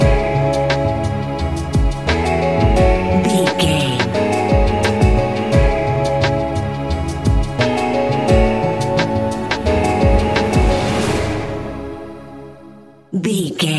Ionian/Major
C♯
laid back
Lounge
sparse
new age
chilled electronica
ambient
atmospheric